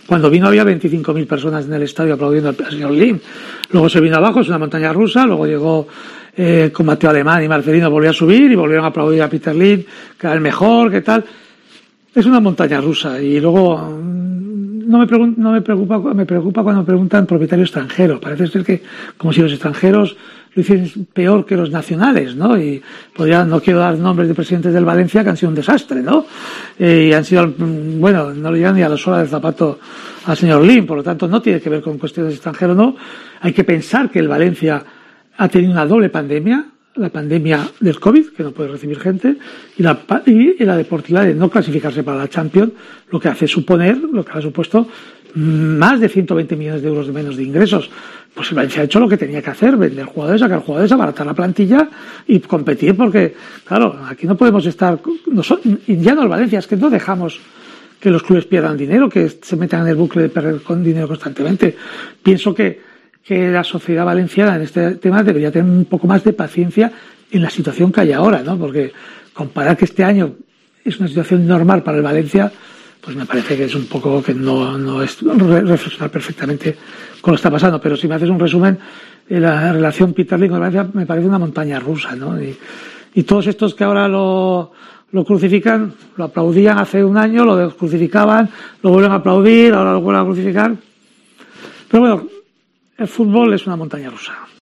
AUDIO. Tebas habla de Lim y del Valencia CF
En esta ocasión, Javier Tebas ha concedido una entrevista al portal 'Futboljobs' y ha realizado un conciso repaso a la actualidad del fútbol español e intenacional y se ha detenido para reflexionar en torno a la situación por la que atraviesa el Valencia CF, la gestión que está llevando a cabo Meriton Holding y la ruptura existente con la sociedad valenciana de un tiempo a esta parte.